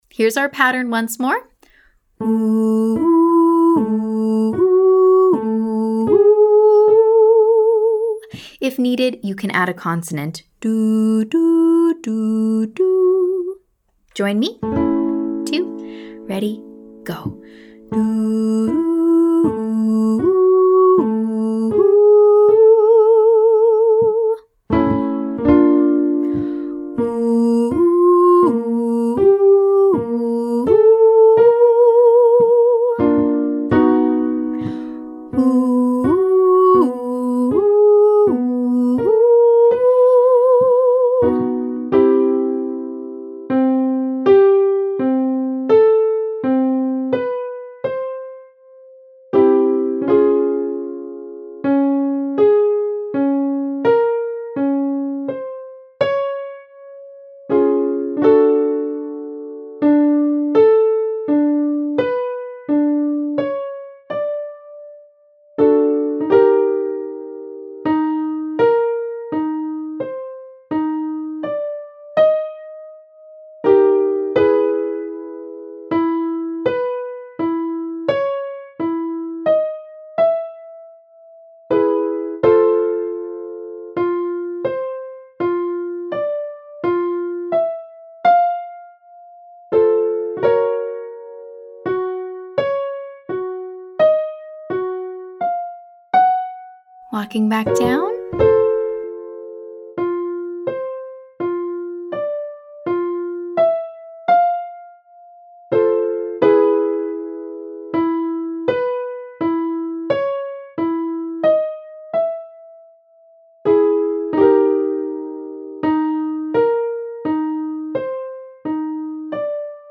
Please be mindful of that lowest pitch: as the interval, or the distance between pitches gets wider, that lowest pitch (your “1”) may become harder to sing in tune.
Vocal Agility Lesson 5A